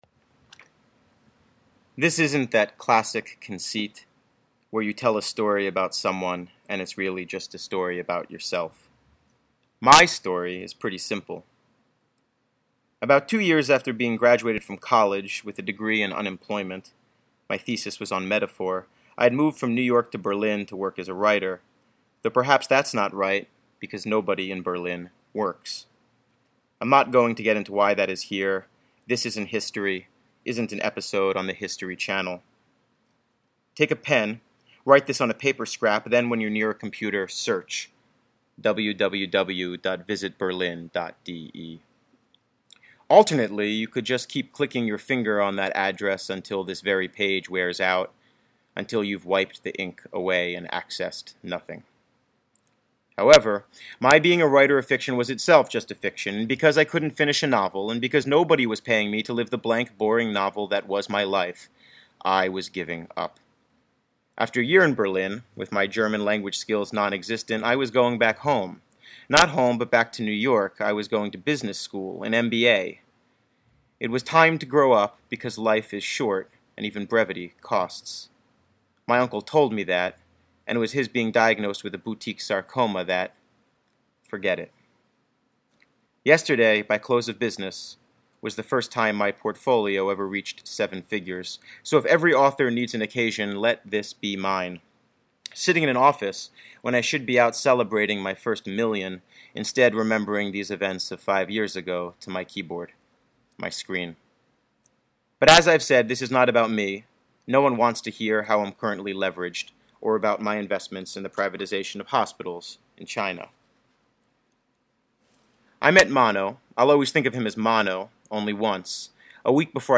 Joshua Cohen reads from the first section of his latest short story collection, Four New Messages, published in August by Graywolf Press